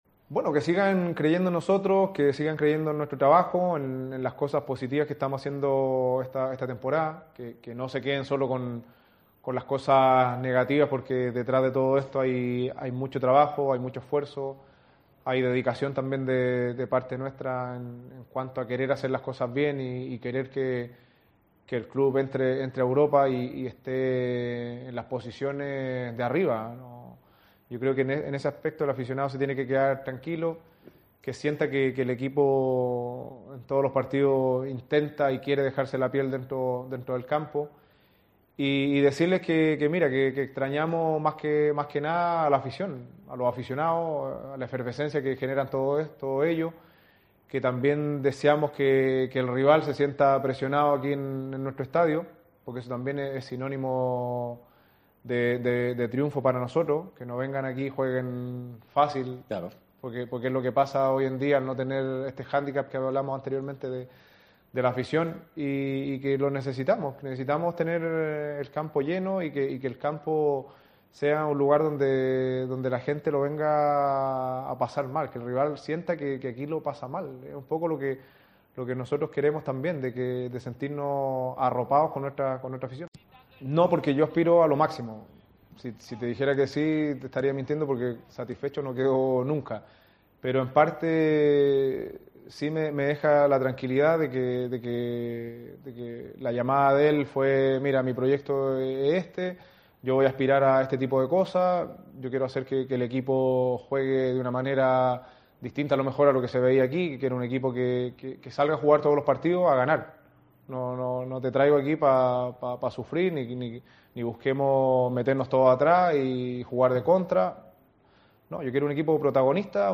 CLAUDIO BRAVO ,EN UNA ENTREVISTA A LOS MEDIOS OFICIALES